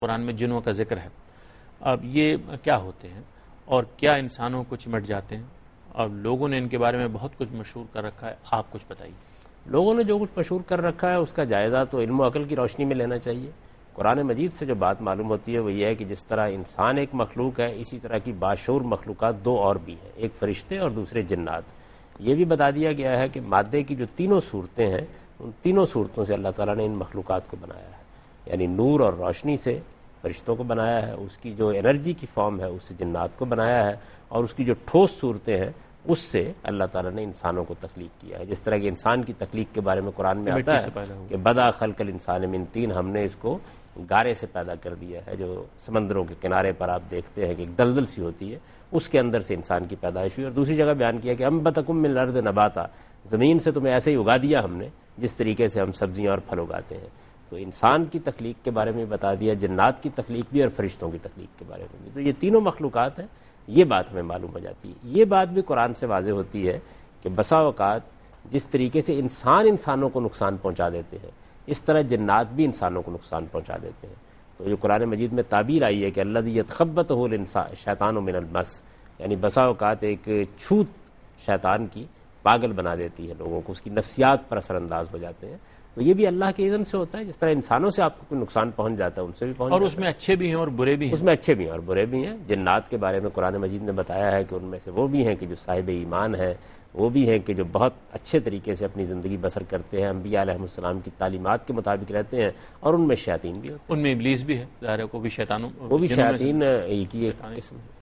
Category: TV Programs / Dunya News / Deen-o-Daanish /
Answer to a Question by Javed Ahmad Ghamidi during a talk show "Deen o Danish" on Dunya News TV